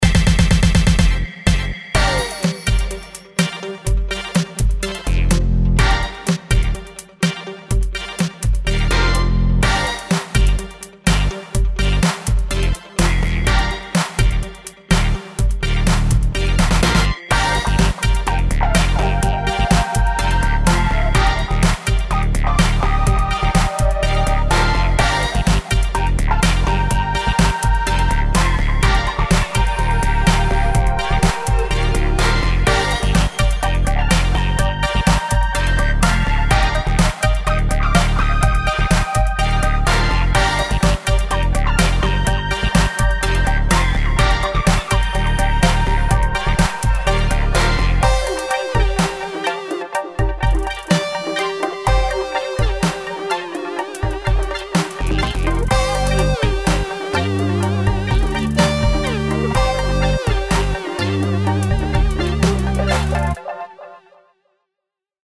Video Game Music